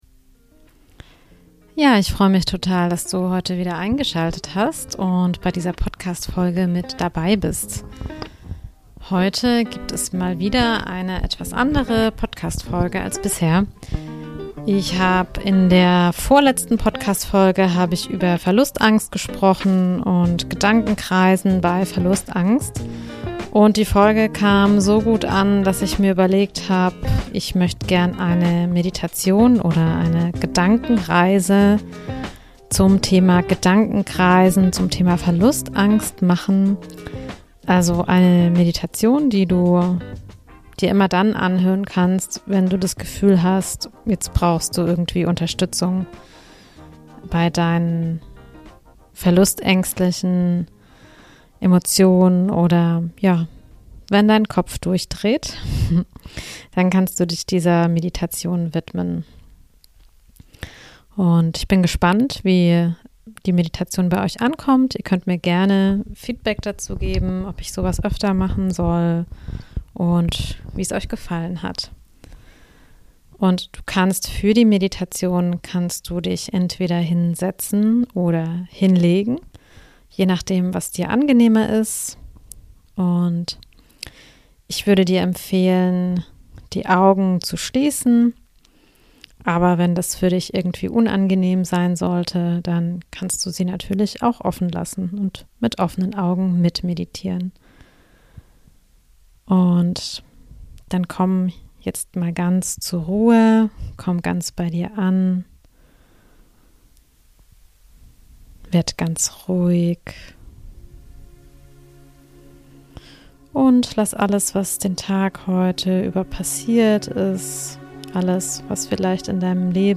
#74 Podcast Special: Meditation - zur Auflösung von Gedankenkreisen. ~ Alles ist Beziehung Podcast
Heute gibt es eine kleine Meditation für euch - viel Spaß dabei!!